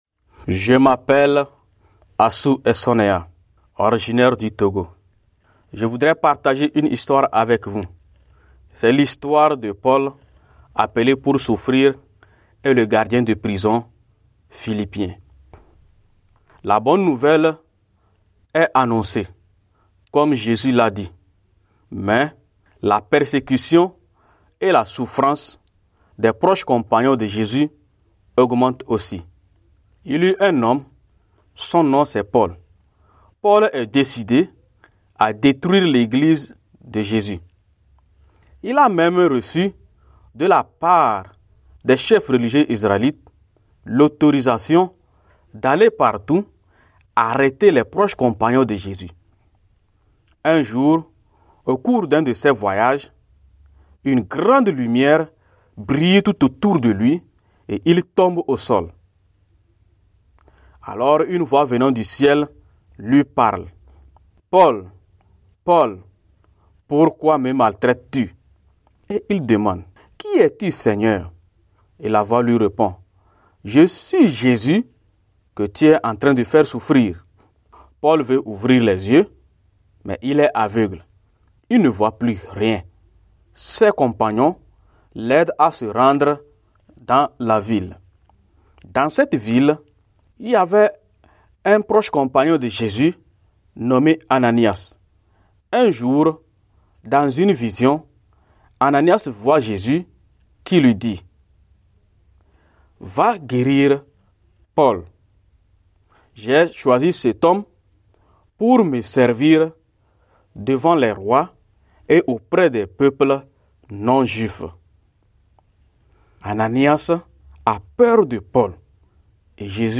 raconter l'histoire de la souffrance de Paul et Silas.